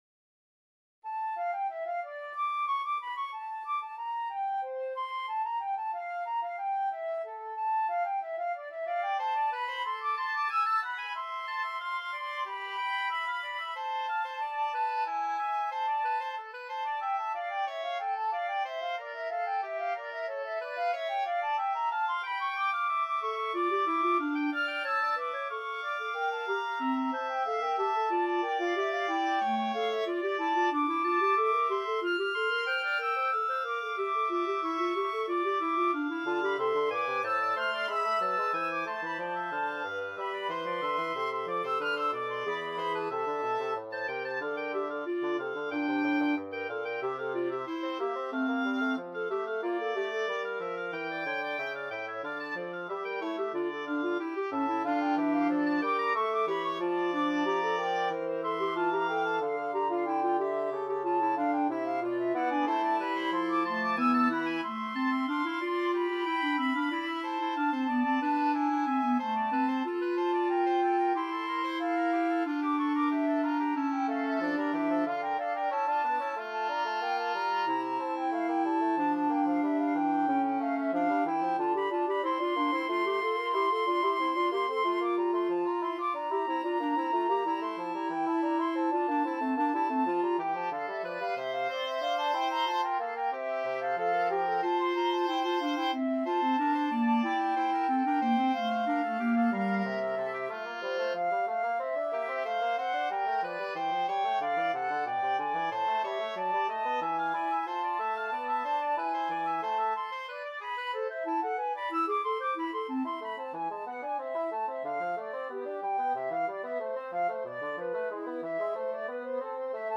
Free Sheet music for Wind Quartet
FluteOboeClarinetBassoon
D minor (Sounding Pitch) (View more D minor Music for Wind Quartet )
4/4 (View more 4/4 Music)
Classical (View more Classical Wind Quartet Music)